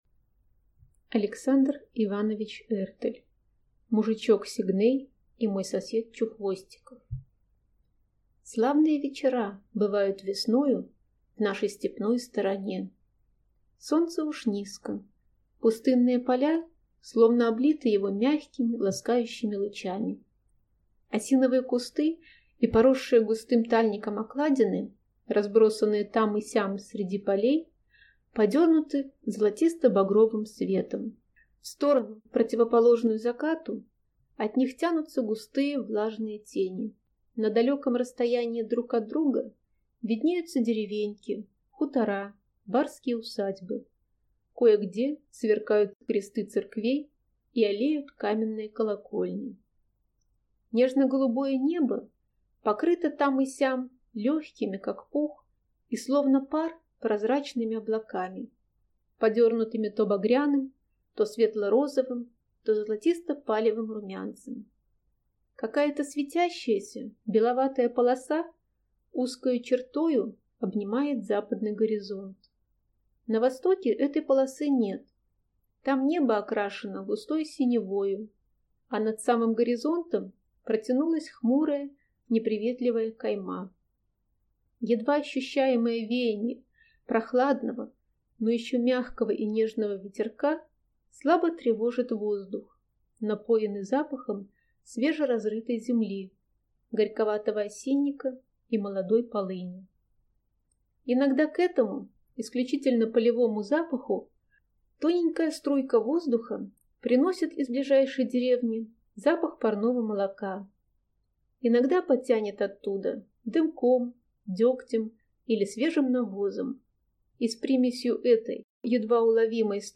Аудиокнига Мужичок Сигней и мой сосед Чухвостиков | Библиотека аудиокниг